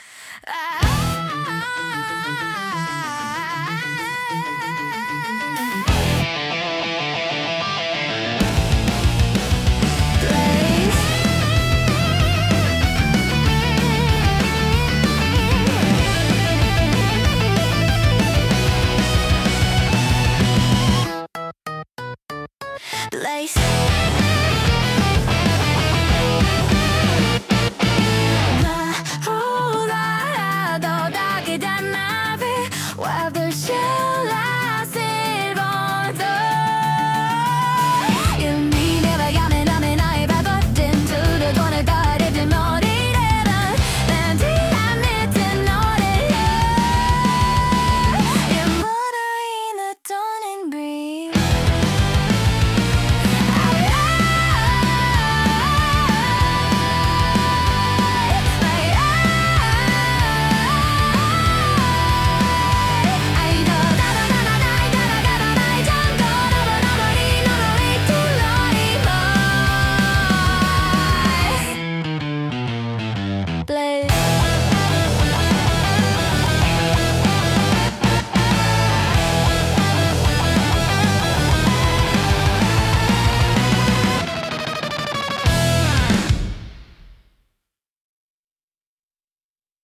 アニメ音楽は、日本のアニメ主題歌をイメージしたジャンルで、ポップでキャッチーなメロディとドラマチックな展開が特徴です。
明るくエネルギッシュな曲調から感動的なバラードまで幅広く、視聴者の感情を引き立てる要素が豊富に詰まっています。